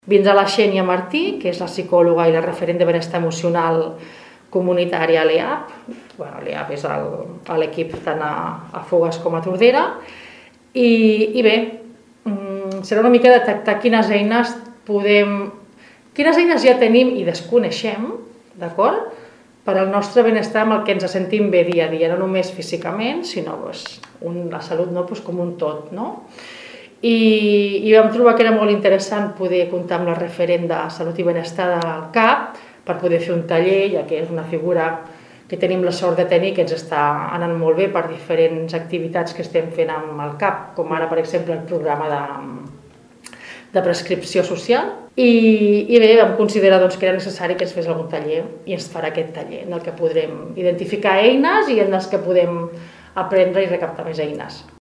Ens en parla la regidora de sanitat, Nàdia Cantero.